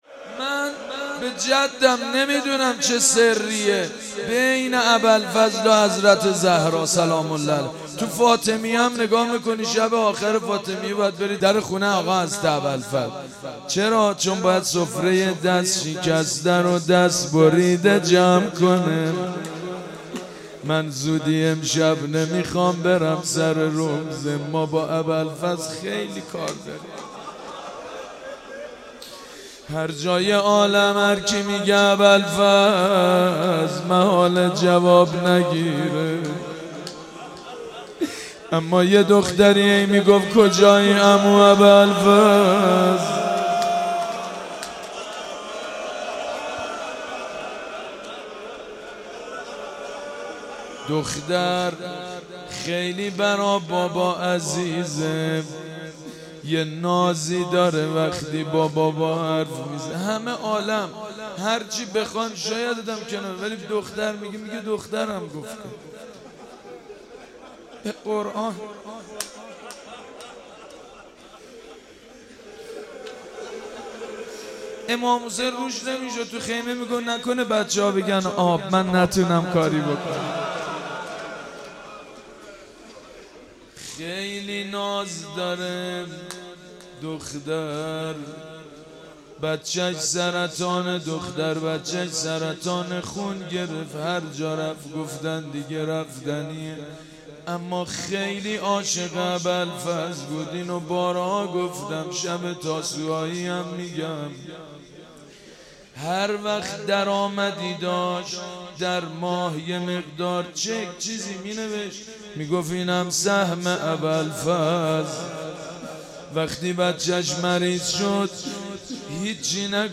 روضه و نوحه شب تاسوعا سال 92 با نوای مداحان اهل بیت
روضه حضرت ابوالفضل(ع)...(بخش سوم)